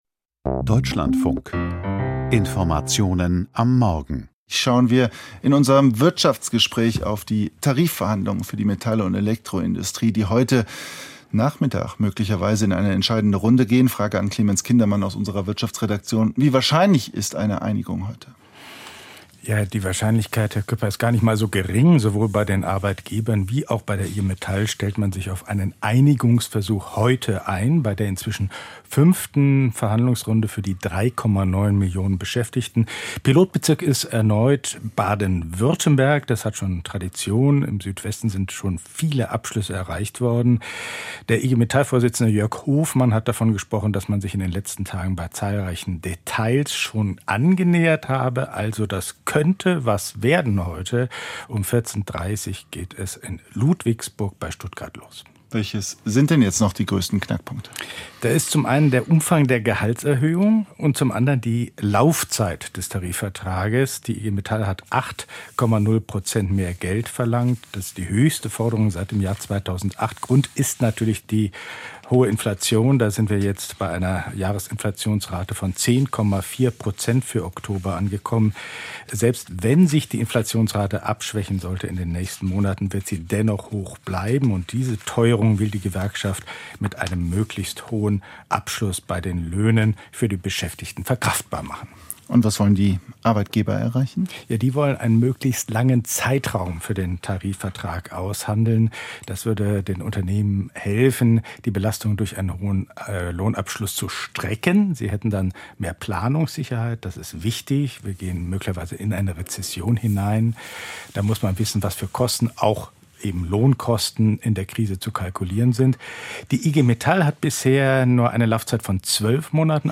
Wirtschaftsgespräch: Metalltarifverhandlungen - Heute der Durchbruch?